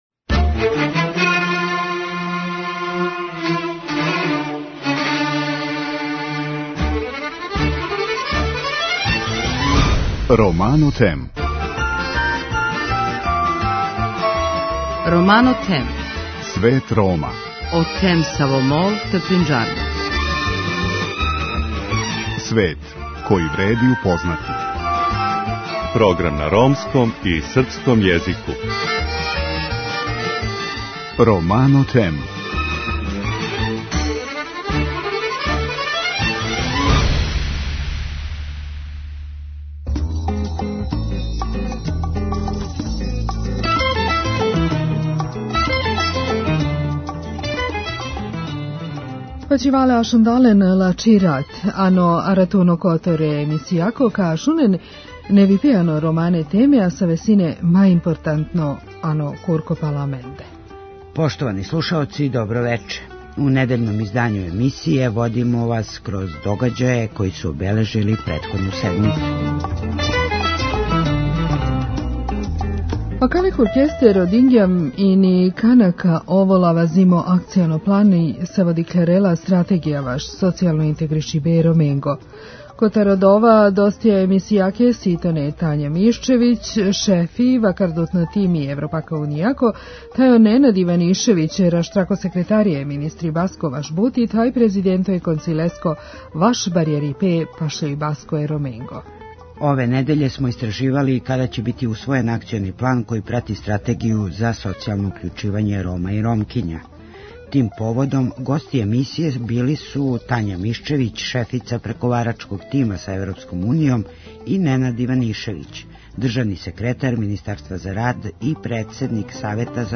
Истраживали смо када ће бити усвојен Акциони план који прати Стратегију за социјално укључивање Рома и Ромкиња. Тим поводом, гости емисије били су Тања Мишчевић шефица преговарачког Тима са Европском унијом и Ненад Иванишевић, државни секретар Министарства за рад и председник Савета за унапређење положаја Рома.